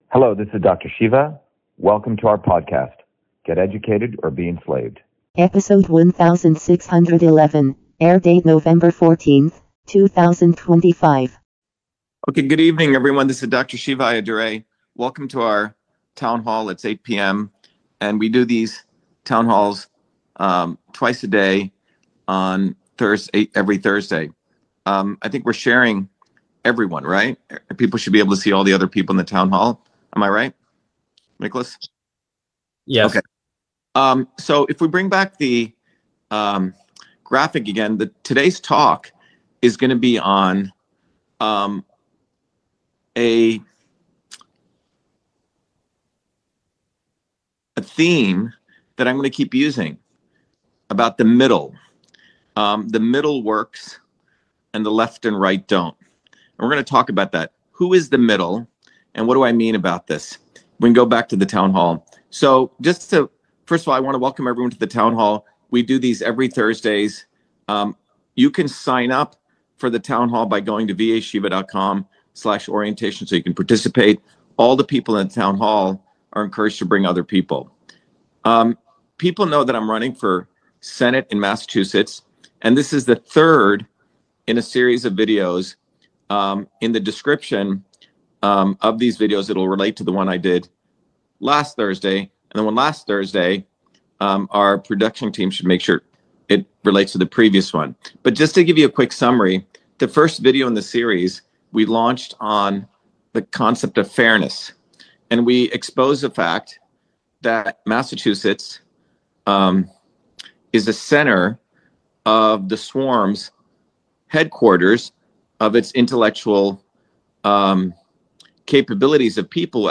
In this interview, Dr.SHIVA Ayyadurai, MIT PhD, Inventor of Email, Scientist, Engineer and Candidate for President, Talks about Shiva4Senate 2026: The Middle Works.